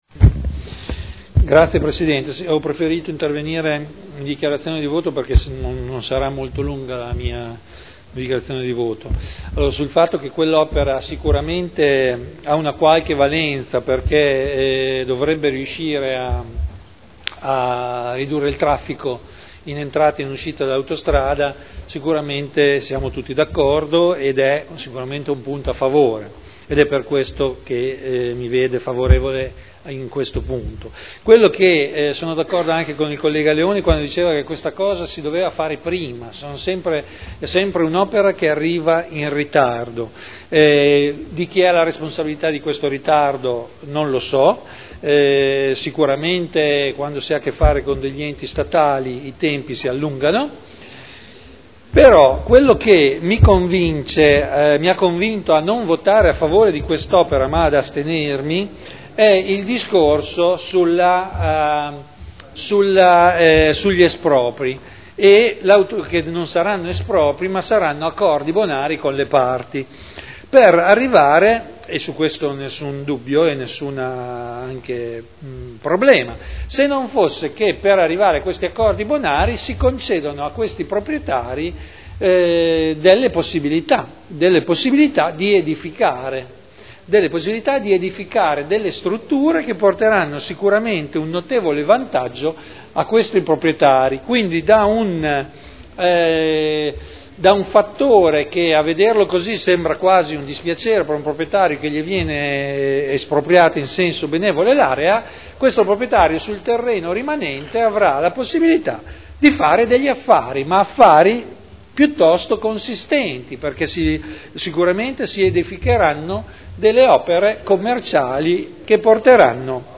Vittorio Ballestrazzi — Sito Audio Consiglio Comunale
Dichiarazioni di voto